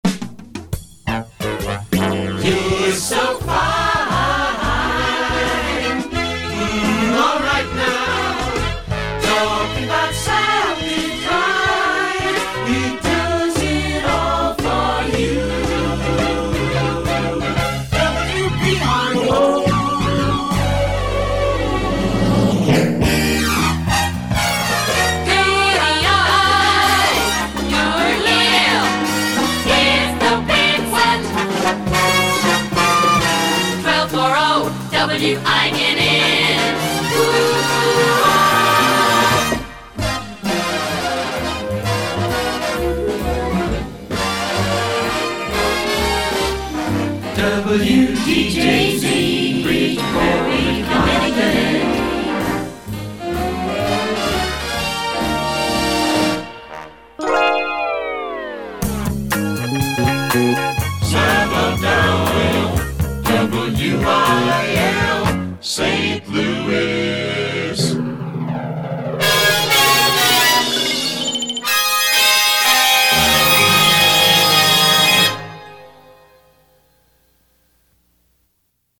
Ouch! that was just a bit painful! (Grin!)